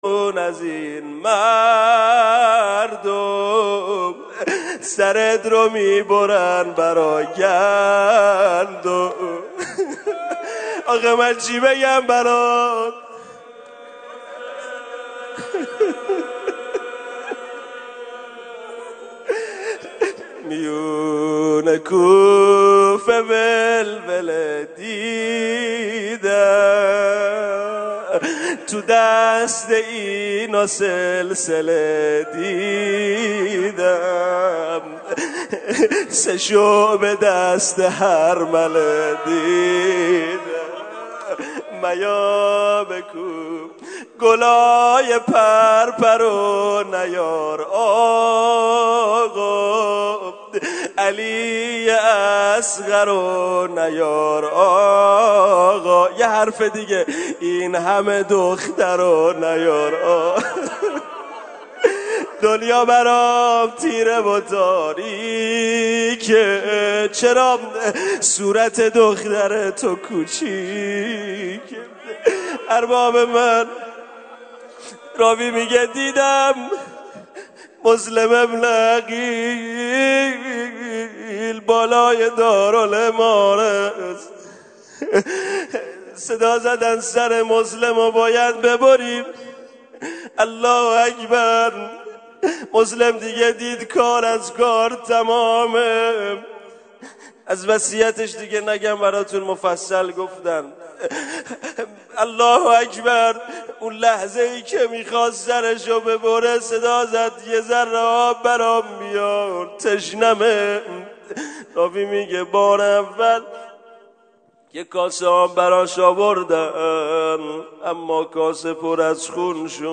rozeh-sh1.m4a